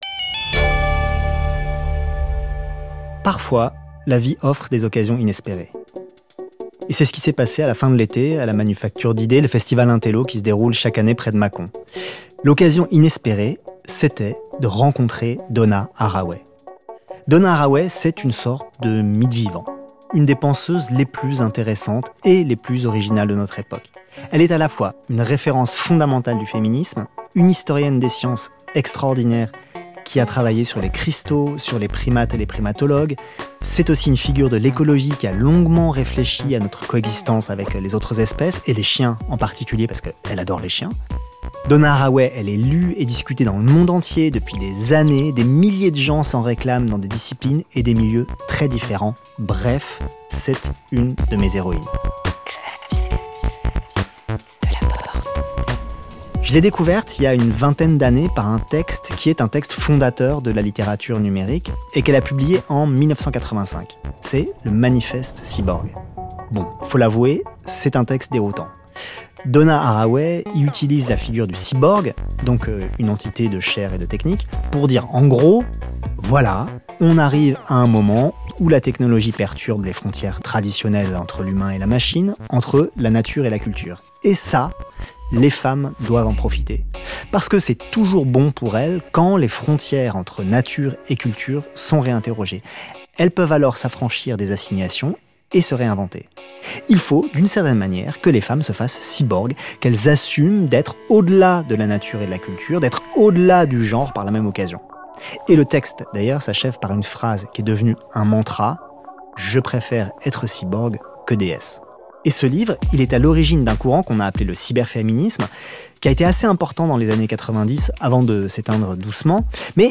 Ecouter Donna Haraway parler de cyborgs sous un arbre
C’est ce qui s’est passé à la fin de l’été, à la Manufacture d’Idées, le festival intello qui se déroule chaque année près de Mâcon. L’occasion inespérée, c'était de rencontrer Donna Haraway.